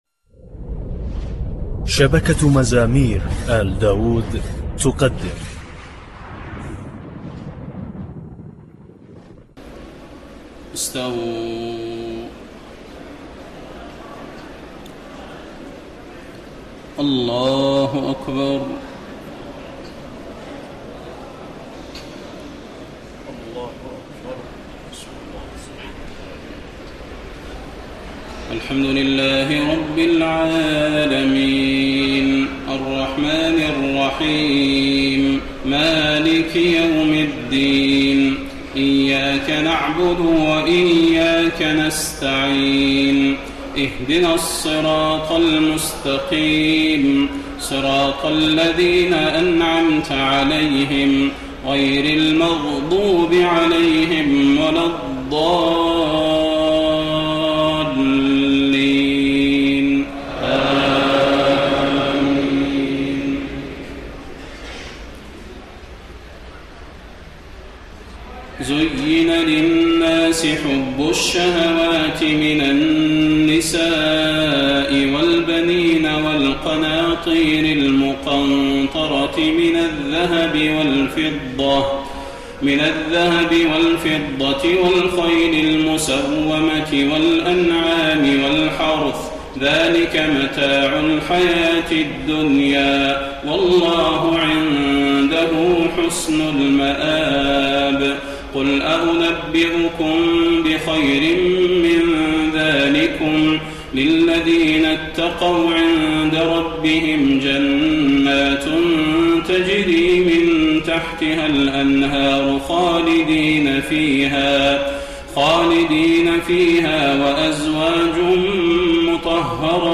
تهجد ليلة 23 رمضان 1431هـ من سورة آل عمران (14-132) Tahajjud 23 st night Ramadan 1431H from Surah Aal-i-Imraan > تراويح الحرم النبوي عام 1431 🕌 > التراويح - تلاوات الحرمين